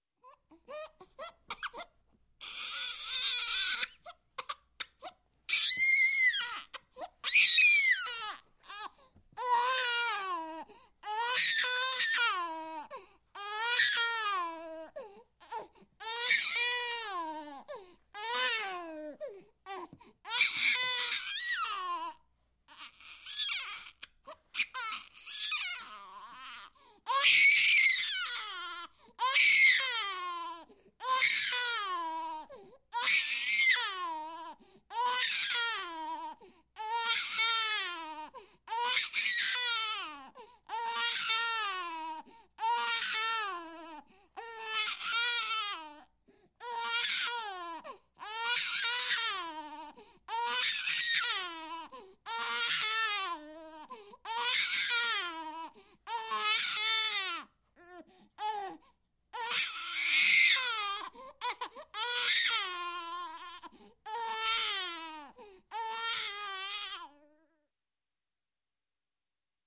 На этой странице собраны различные звуки плача младенцев: от тихого хныканья до громкого крика.
Плач двухмесячного малыша